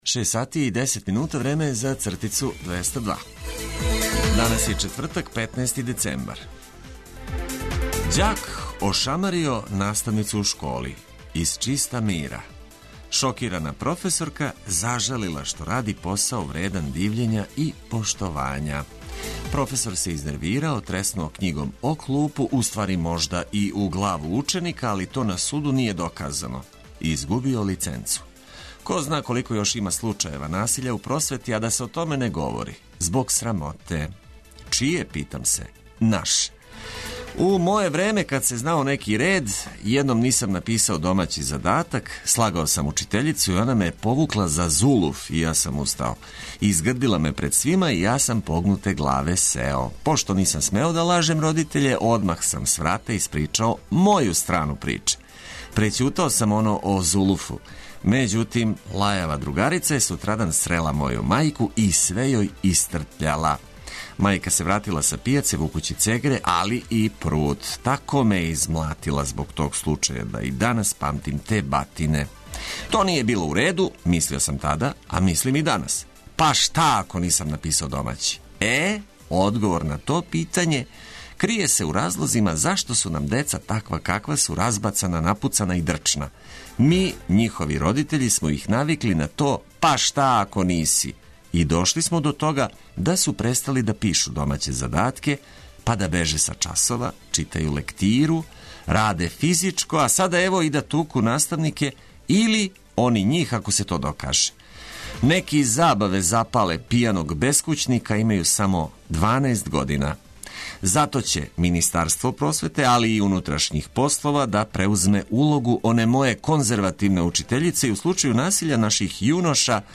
Чекајући свануће уз музику за лепше разбуђивање објављиваћемо кратке али важне информације.